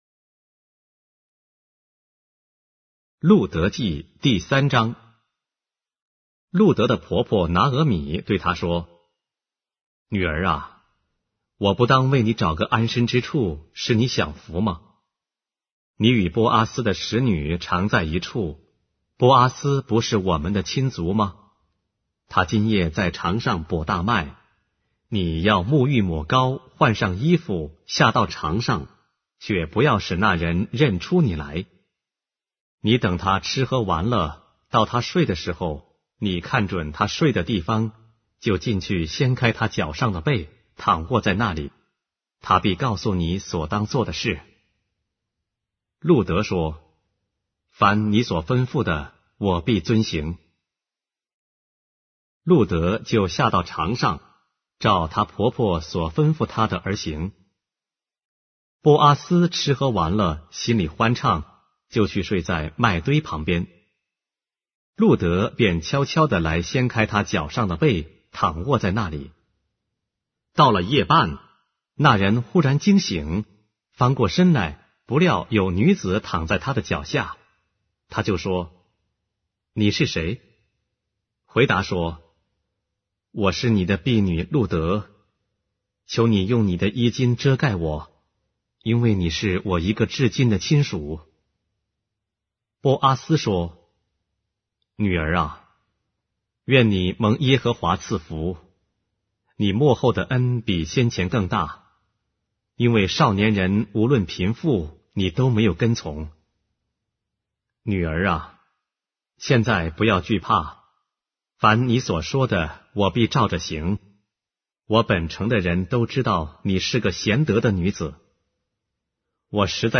书的圣经 - 音频旁白 - Ruth, chapter 3 of the Holy Bible in Simplified Chinese